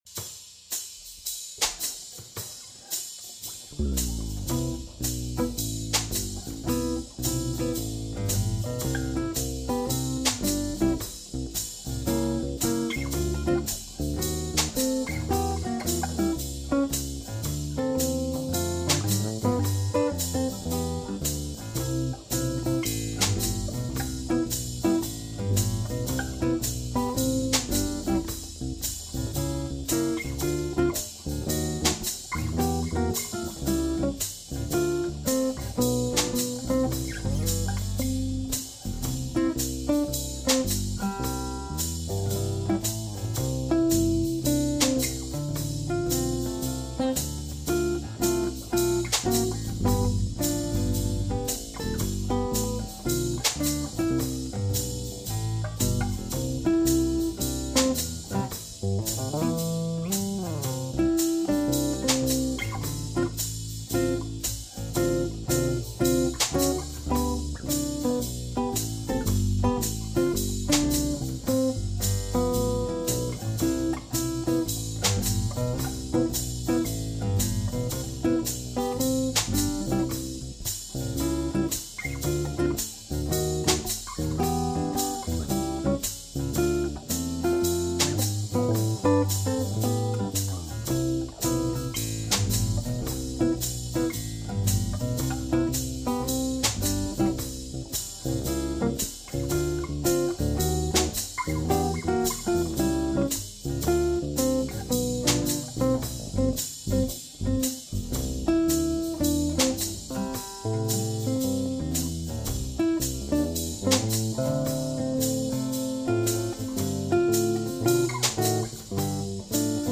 jazzy version of jingle bells
Jingle_Bells in G music and lyrics